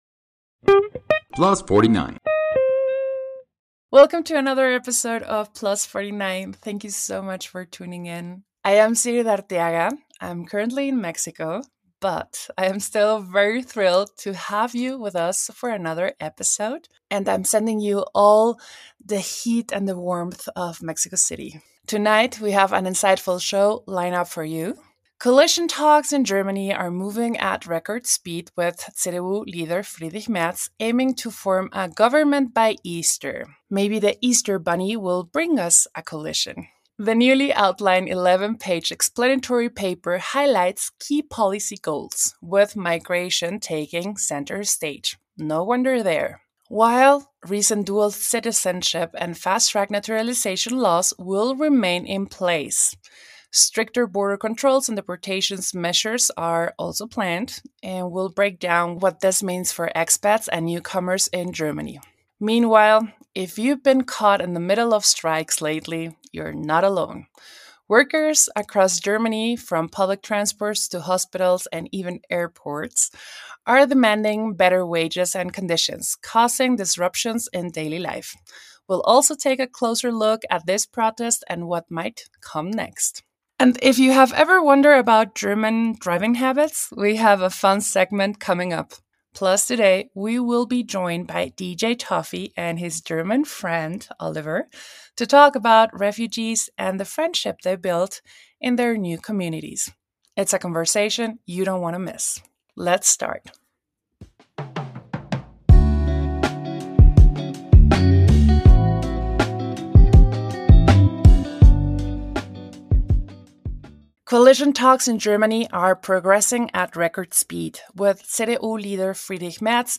a very special conversation